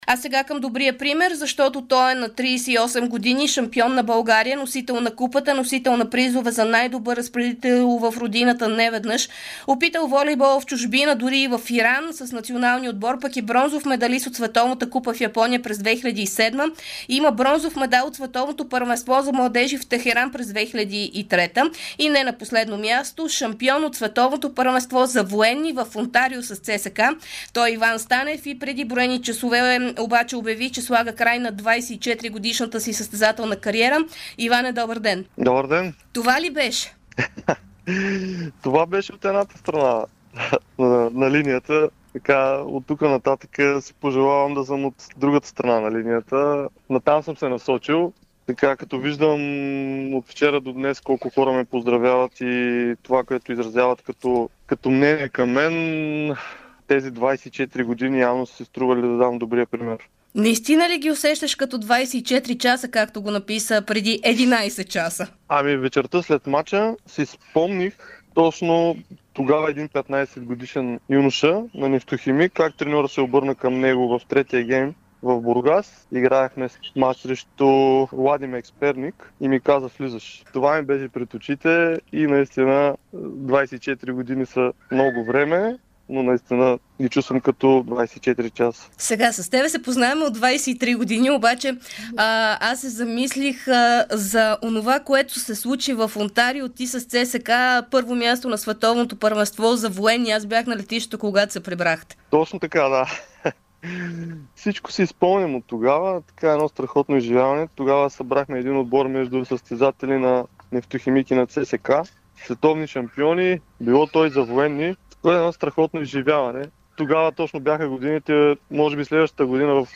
ексклузивно интервю пред Дарик радио и dsport.